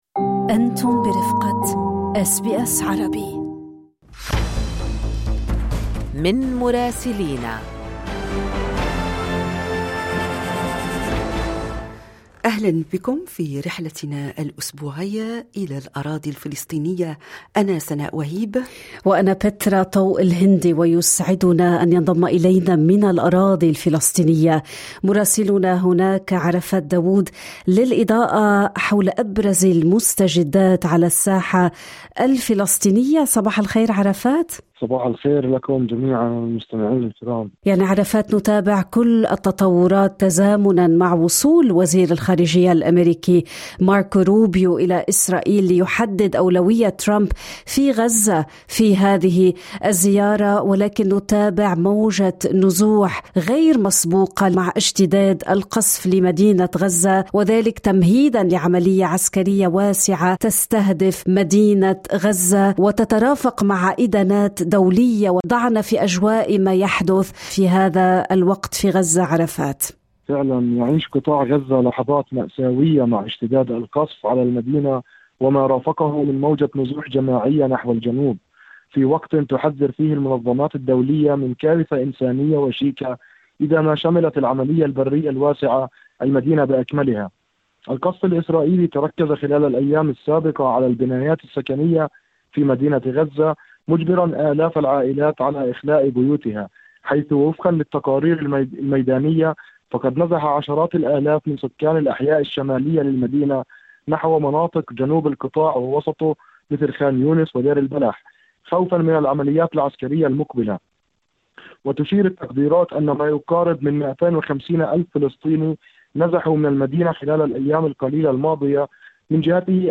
من مراسلينا